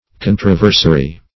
Controversary \Con`tro*ver"sa*ry\, a.